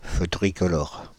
Ääntäminen
France (Île-de-France): IPA: /fø tʁi.kɔ.lɔʁ/